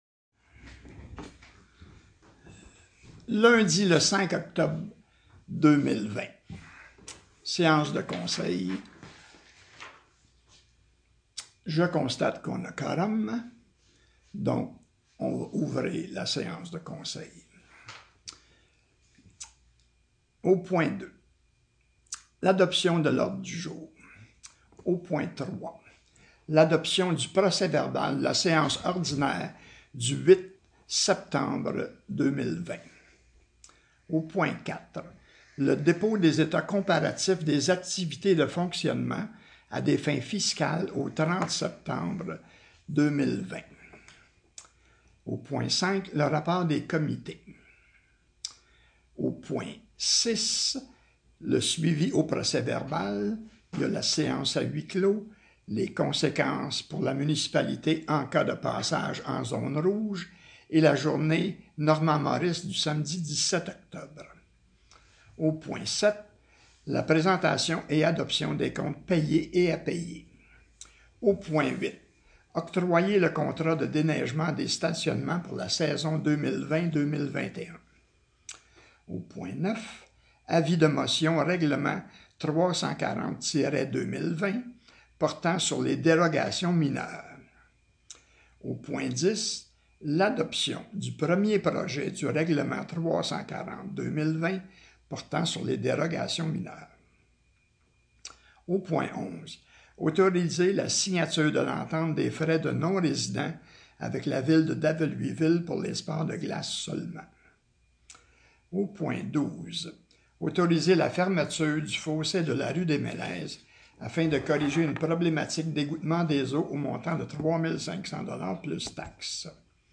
Séance ordinaire huis clos 5 Octobre 2020 – Municipalité de Saint-Louis-de-Blandford